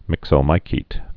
(mĭksō-mīsēt)